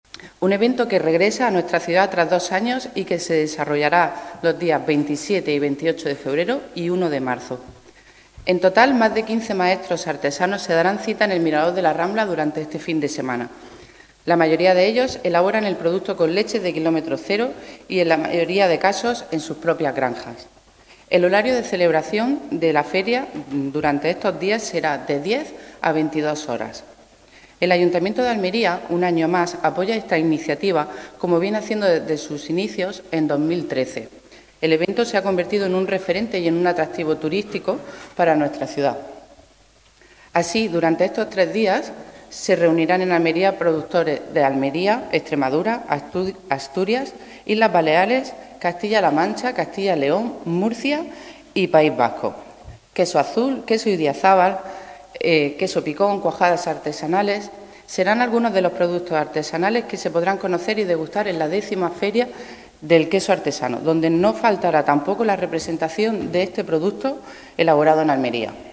ANA-TRIGUEROS-CONCEJAL-COMERCIO.mp3